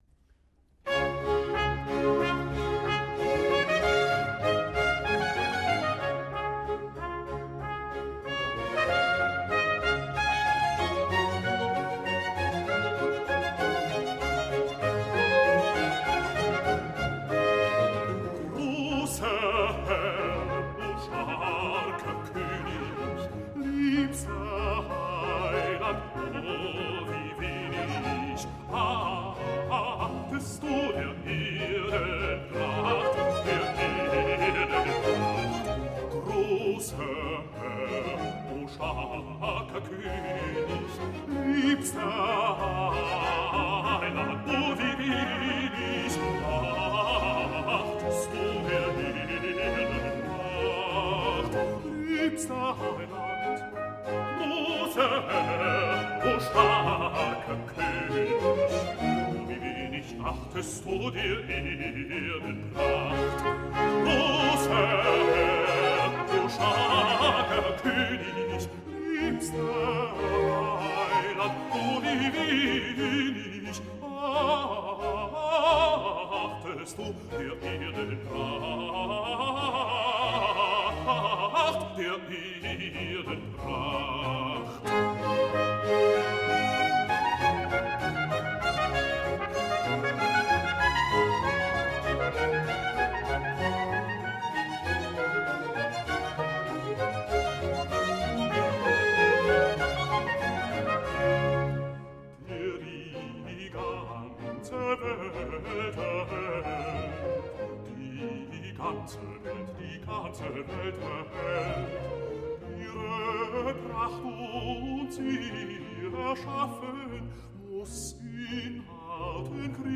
The cantata ends with a chorale, but immediately before this moment of communal singing and reflection, Bach stages an emphatic reminder of the irrelevance of the self-serving glories of earth in the brash bass aria Grosser Herr, o starker König (BWV 248I/8):
Audio Example 6: BWV 248I/8: Großer Herr, o starker König”; John Eliot Gardiner and the English Baroque Soloists and Monteverdi Choir; courtesy of Deutsche Grammophon
In spite of the text’s disclaimers regarding earthly extravagance, this very festive music was originally written precisely for such purposes, that is, to proclaim the earthly fame of the Saxon Electoral House.
This is a difficult assertion to maintain, however, not only because of the origins of the aria, but because the music unabashedly exudes pomp.